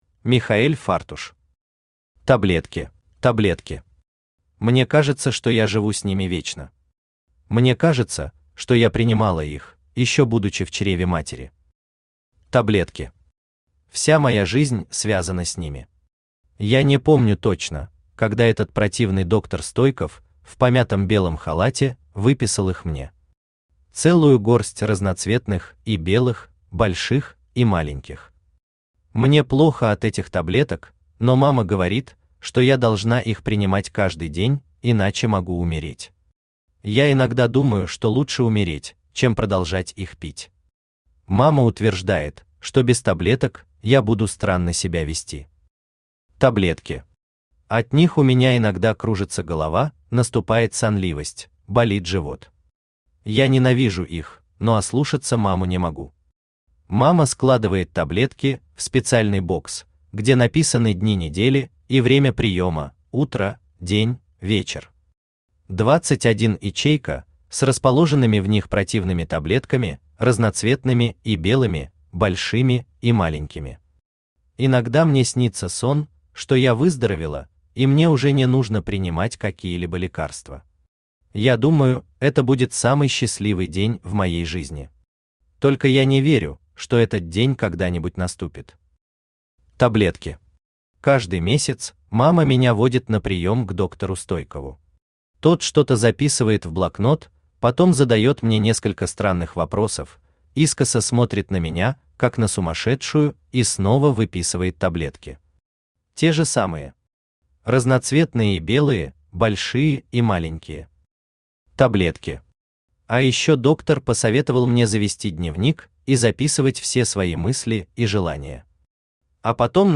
Аудиокнига Таблетки | Библиотека аудиокниг
Aудиокнига Таблетки Автор Михаэль Фартуш Читает аудиокнигу Авточтец ЛитРес.